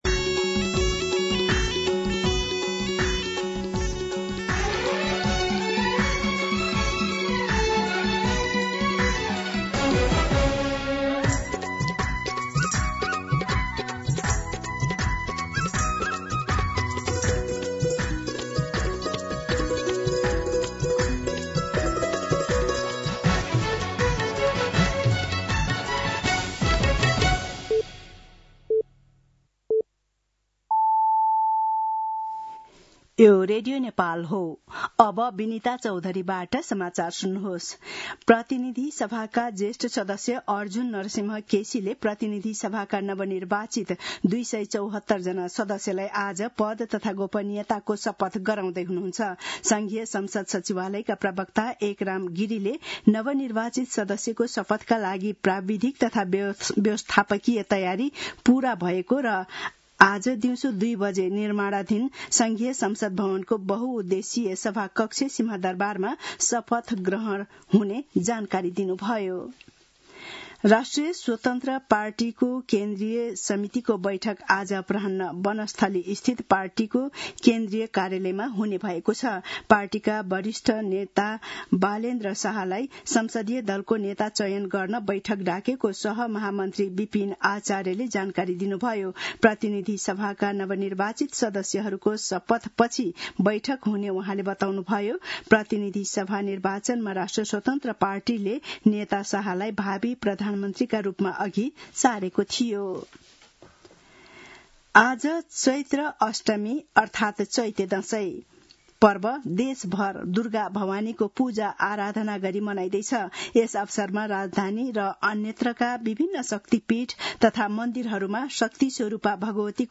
दिउँसो १ बजेको नेपाली समाचार : १२ चैत , २०८२